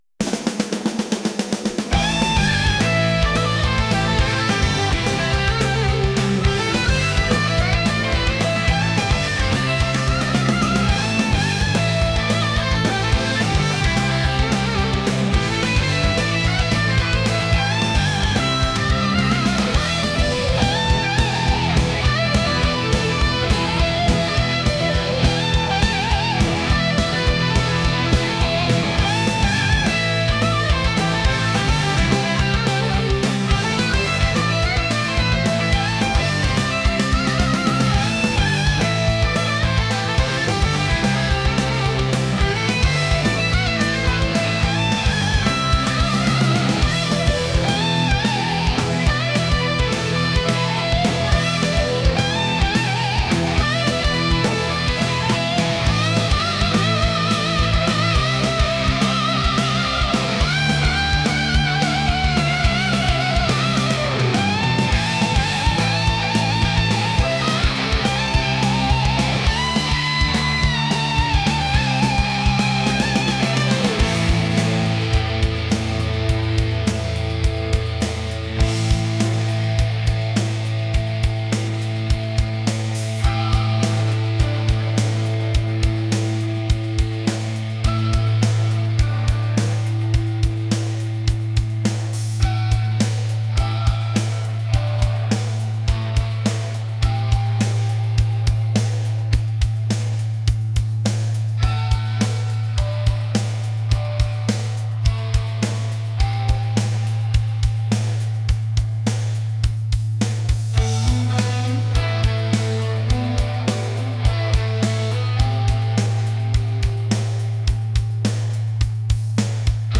rock | energetic